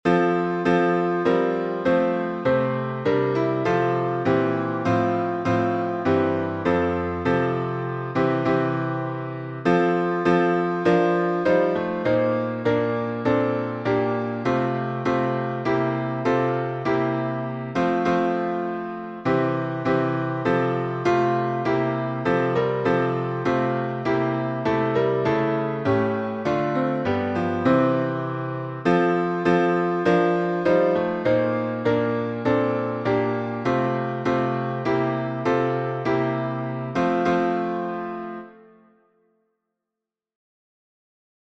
Key signature: F major (1 flat) Time signature: 4/4